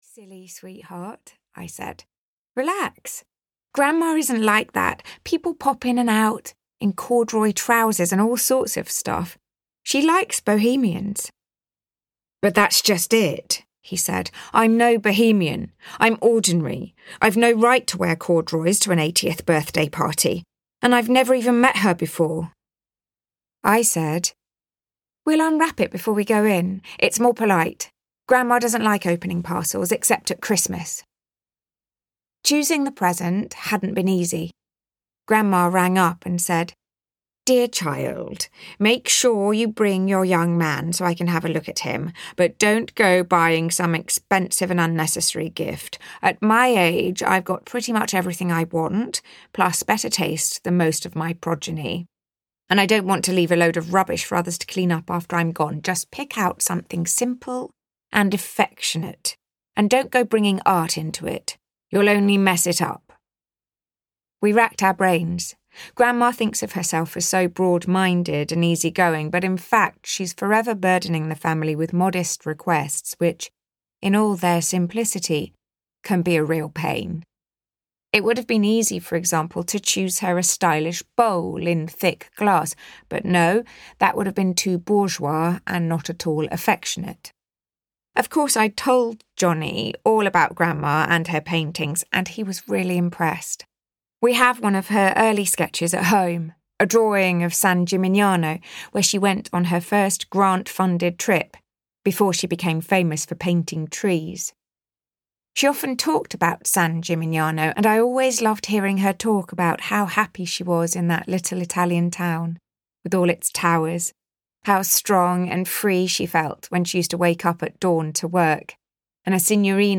Travelling Light (EN) audiokniha
Ukázka z knihy
• InterpretLouise Brealey